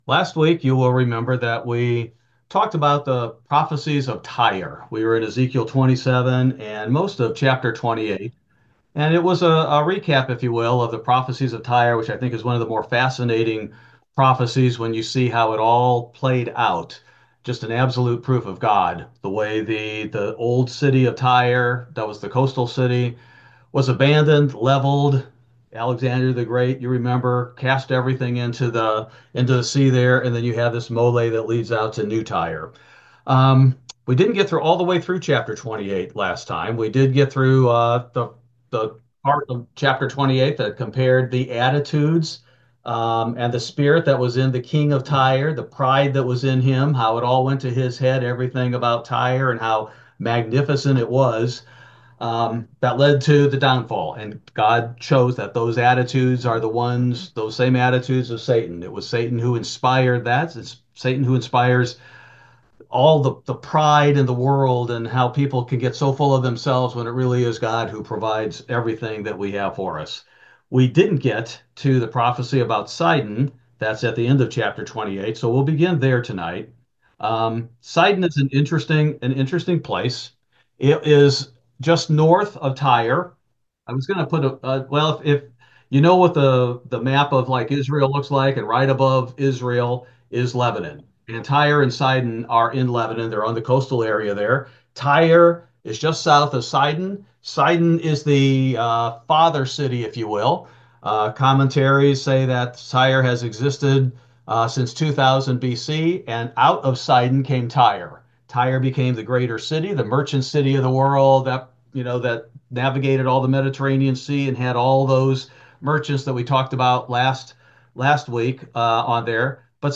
This verse by verse Bible Study primarily covers Ezekiel chapters 29-30